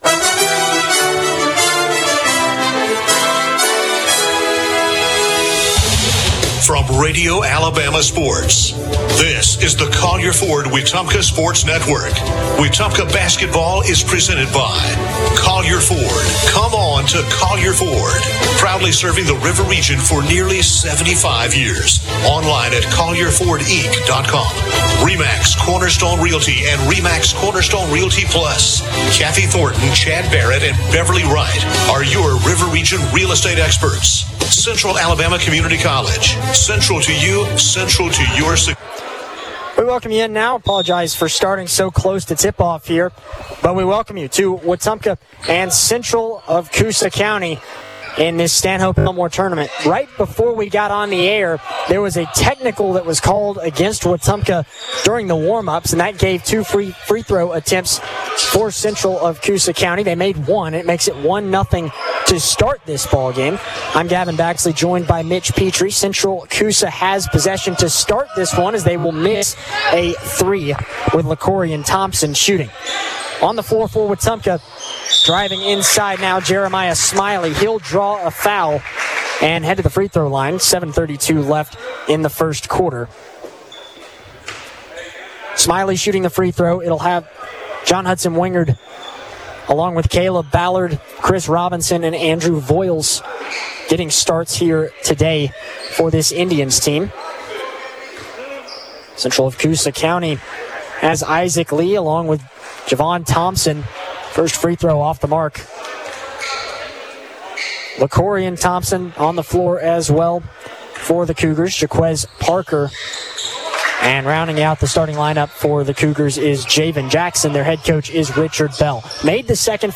(Boys Basketball) Wetumpka vs. Coosa Central